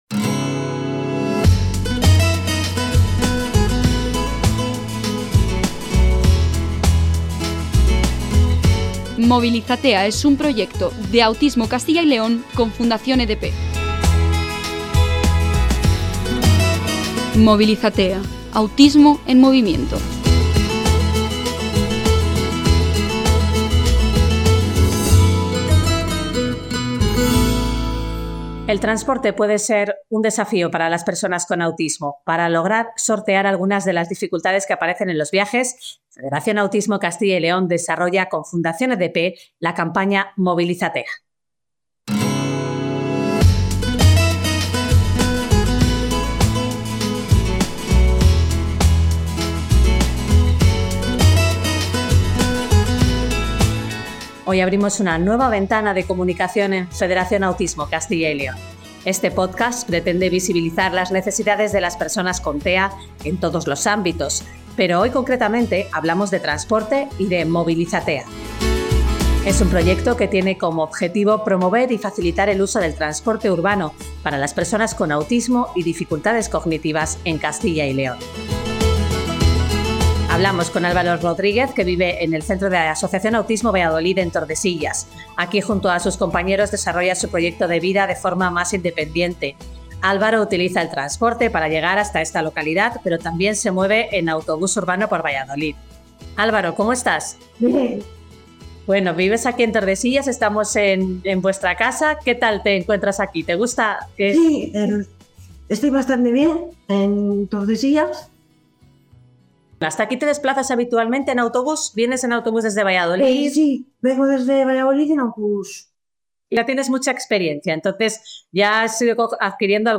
Nos desplazamos hasta la vivienda que Autismo Valladolid tiene en Tordesillas. Allí hemos grabado el primer capítulo del ‘Podcast MovilizaTEA, Autismo en movimiento’.